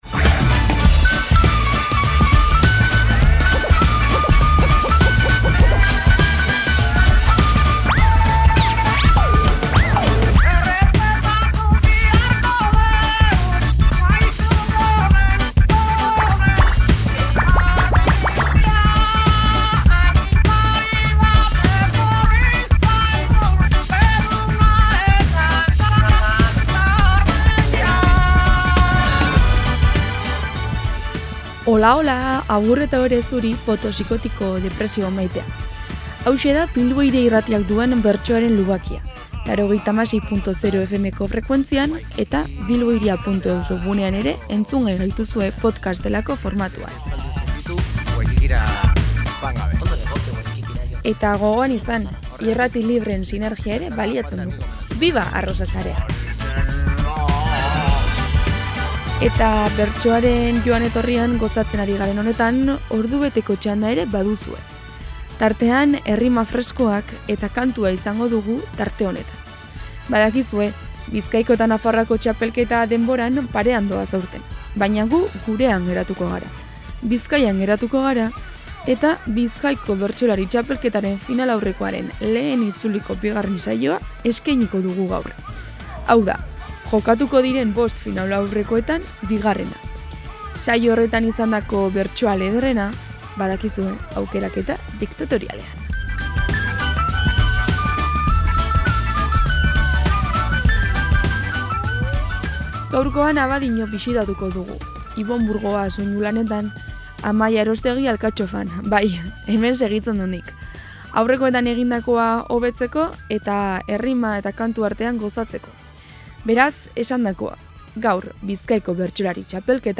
Bizkaiko Bertsolari Txapelketako finalaurrekoek aurrera egin dute. Abadiñon izan da lehenengo itzuliko bigarren saioa, eta bertsoen aukeraketa diktatoriala duzue entzungai gaurko saioan.